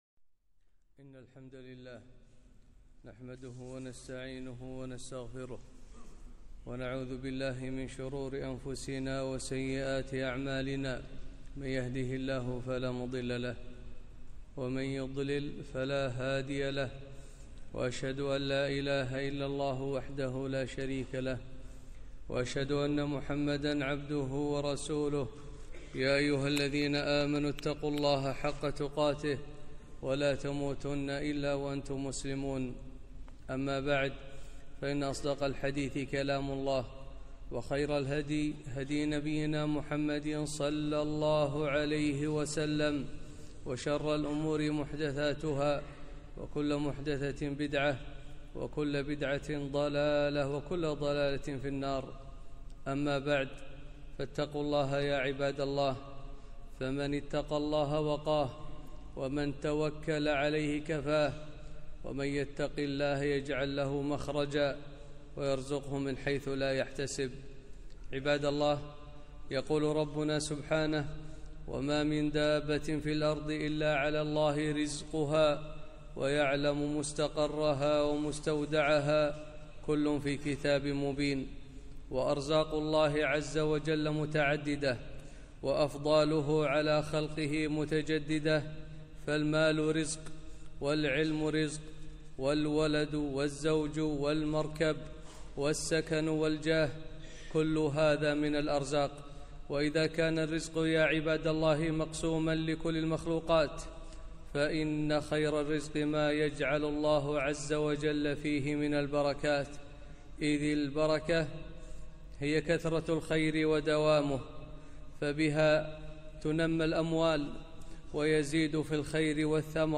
خطبة - البركة في حياة المسلم